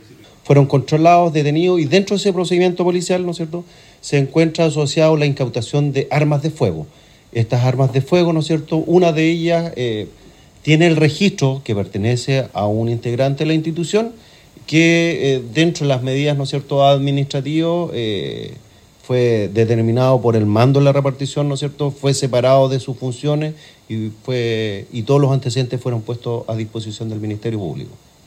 La información fue confirmada a Radio Bío Bío por el jefe de la Novena Zona de Carabineros de La Araucanía, el general Patricio Yáñez.